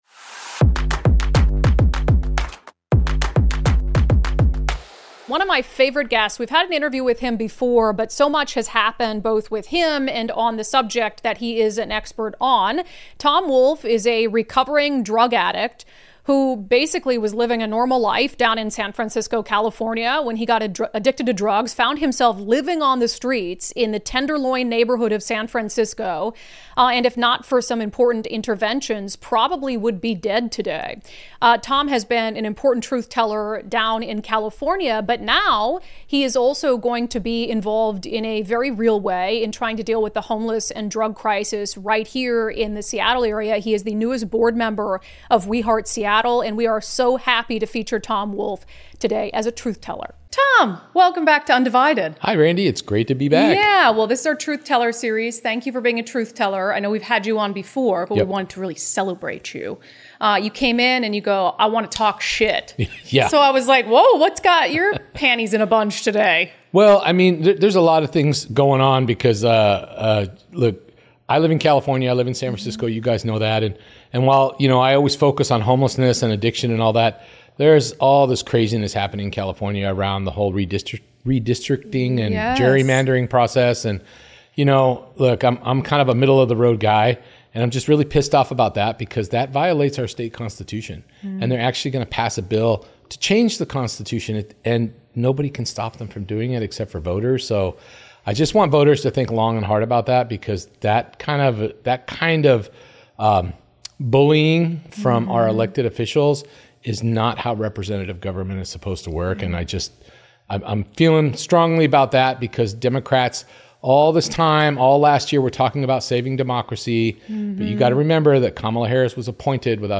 Headliner Embed Embed code See more options Share Facebook X Subscribe unDivided brings you a special interview series, celebrating important "Truth Tellers" from all sides of politics and culture.